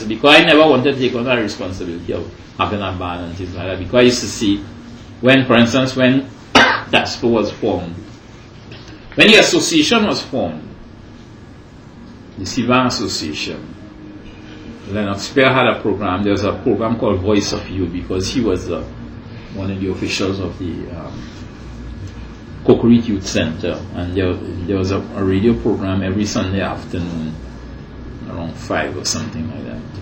5 audio cassettes